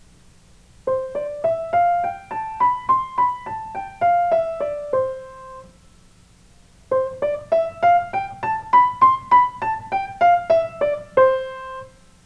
piano-c5-c6.wav